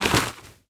Dirt footsteps 1.wav